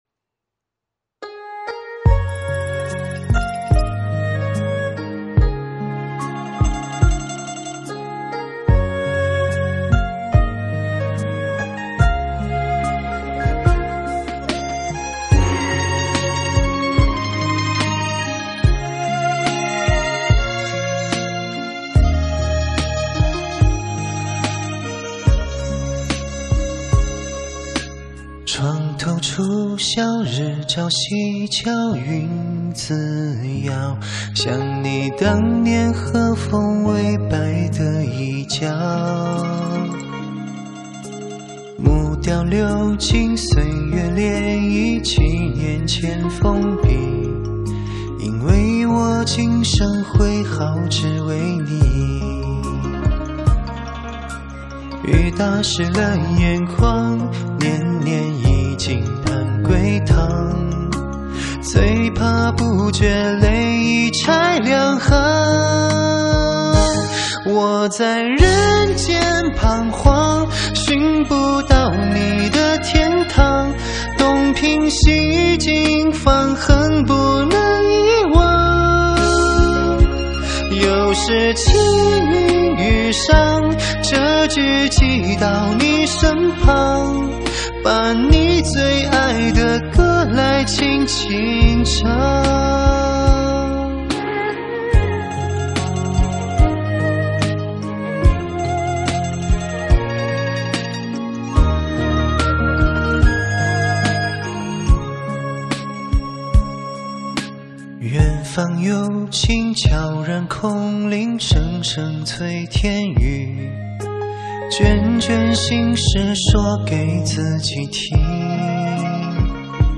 轻轻柔柔的声音
柔柔的，可爱又好听
感情饱满。